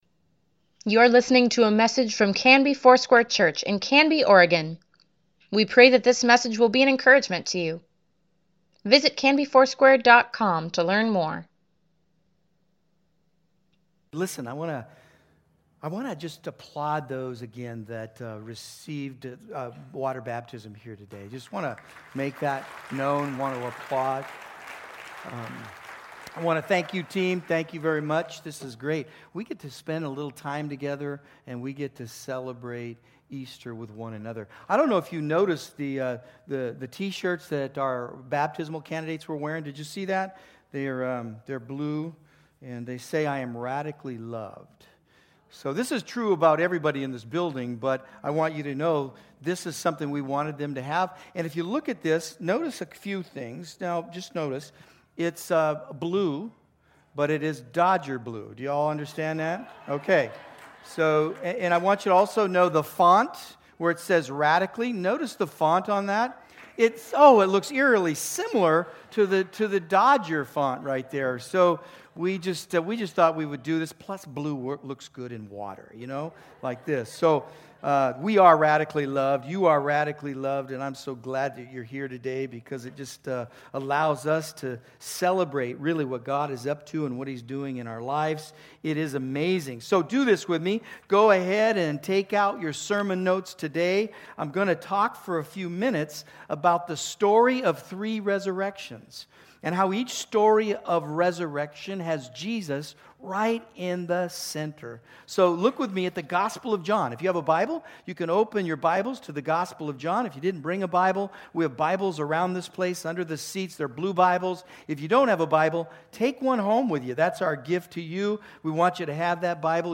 Weekly Email Water Baptism Prayer Events Sermons Give Care for Carus The Story of 3 Resurrections April 21, 2019